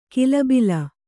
♪ kilabila